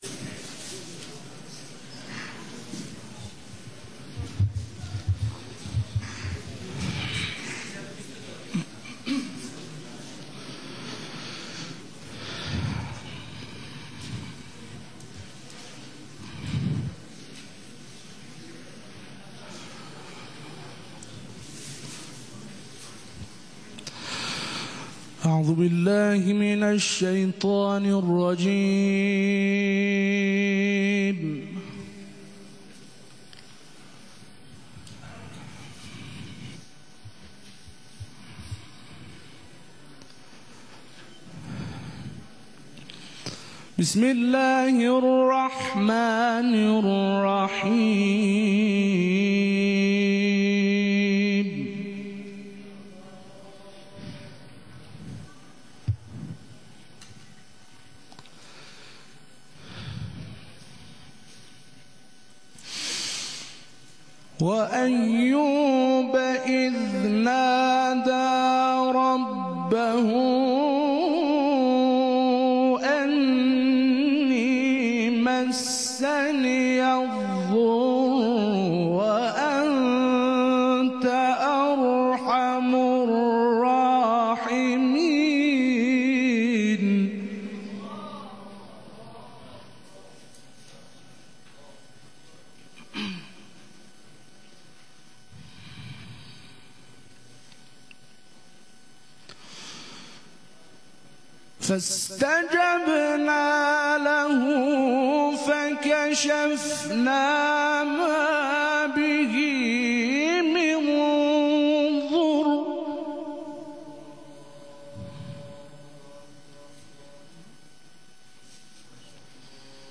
صوت رقابت های ارزیابی قاریان اعزامی به مسابقات جهانی + دانلود
گروه فعالیت های قرآنی: دهمین دوره ارزیابی قاریان و حافظان قرآن کریم که از روز چهارشنبه ۱۷ آذرماه در مرکز امور قرآنی سازمان اوقاف و امور خیریه آغاز شده است، امروز ۱۹ آذرماه در امامزاده حسن(ع) تهران در حال برگزاری است.